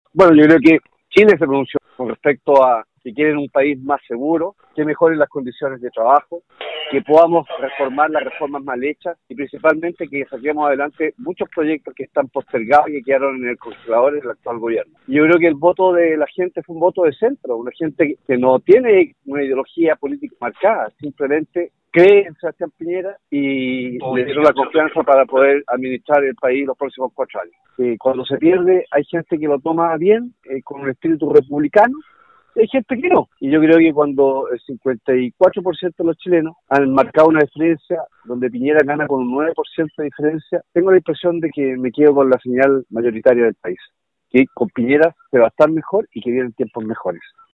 Conversamos con el electo diputado Gabriel Ascencio quien desde la localidad de Chaitén dijo que este resultado le hace mal al país y que son 4 años perdidos, pero hay que seguir trabajando para que las reformas se cumplan. En tanto el diputado Alejandro Santana sostuvo que Chile se pronunció con el 54 por ciento a favor del candidato de Chile Vamos y no cree que un país pierda, todo lo contrario, habrá tiempos mejores.